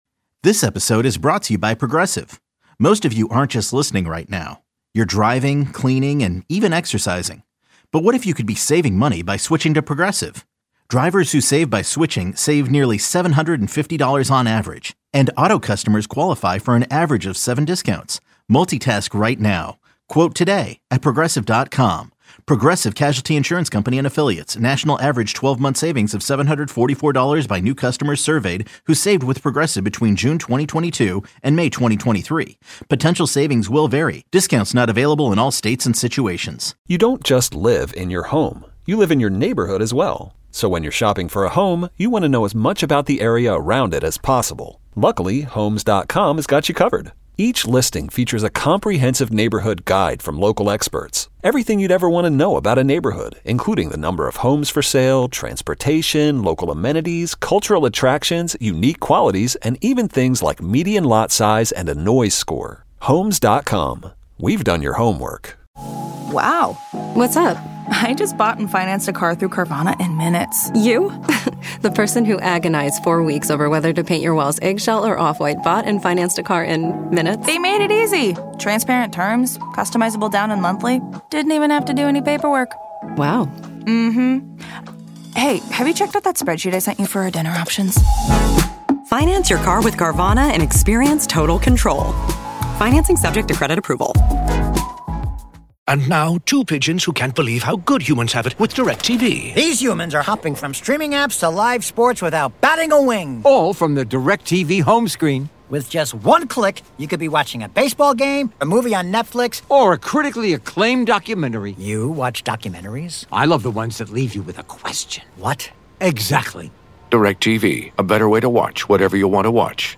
Wichita's popular morning local sports talk radio show